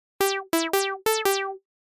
알림음 8_준비됐나요1.mp3